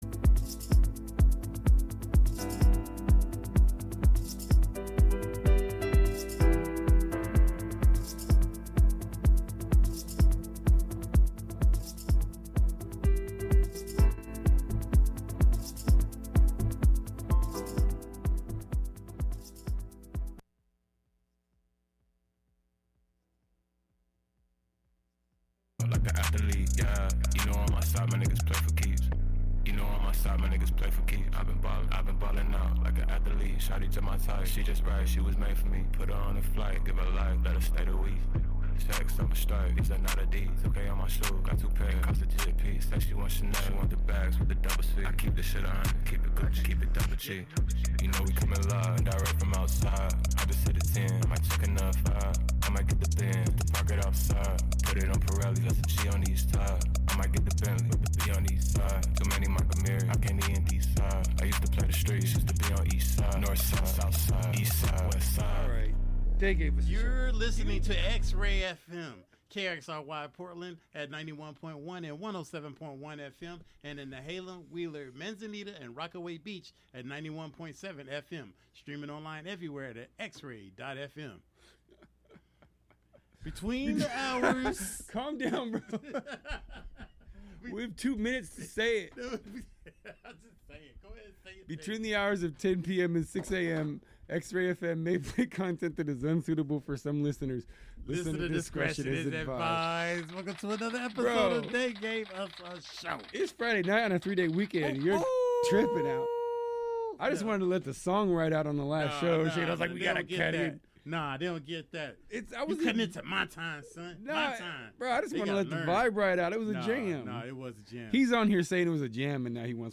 New and throwbacks, from Portland and beyond. Plus, catch interviews from talented artists.